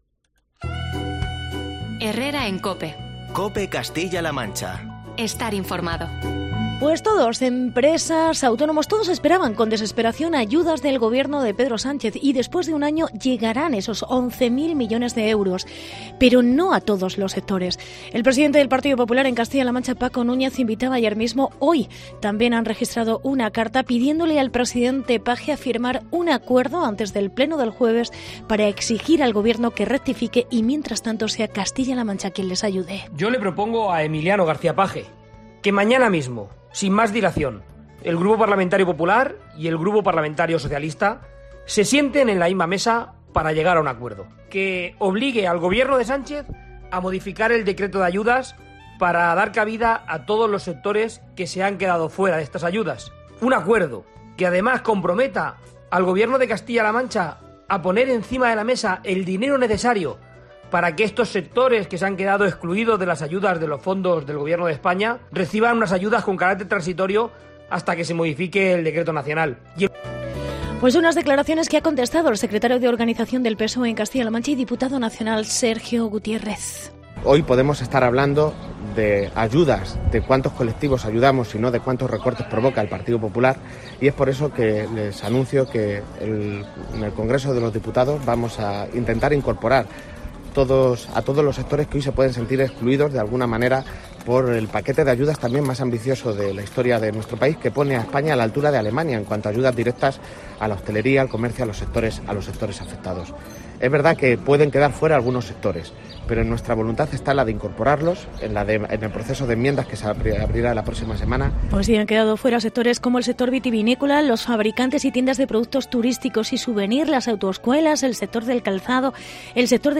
Reportaje sobre sectores excluidos de las ayudas